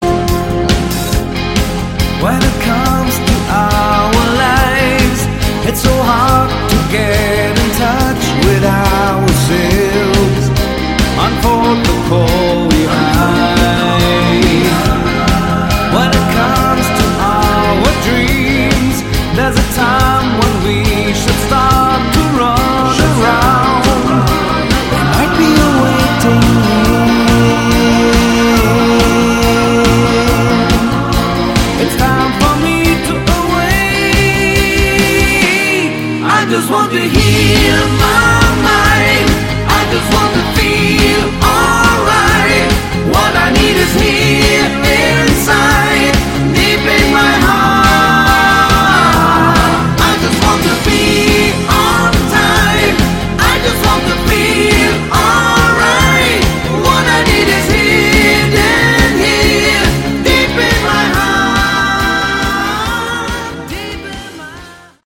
Category: AOR
vocals
keyboards
guitars
drums
bass